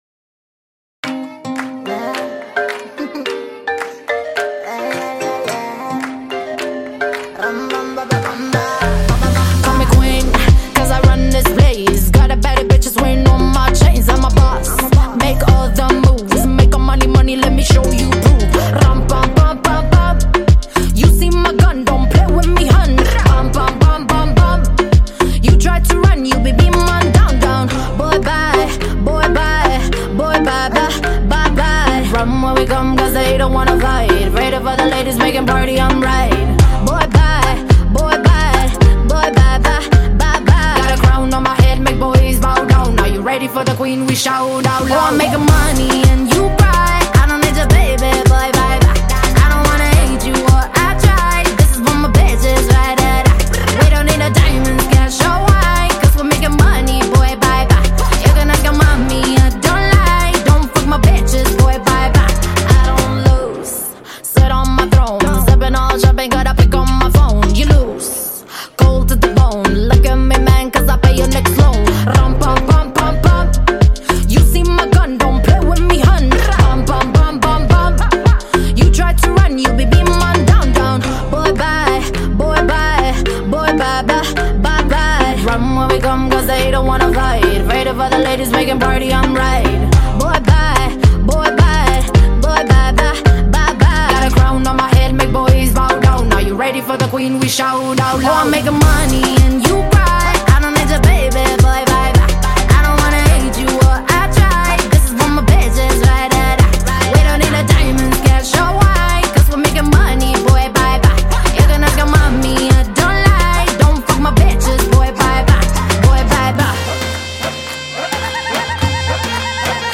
دانلود ریمیکس آهنگ
با صدای زن میکس تند بیس دار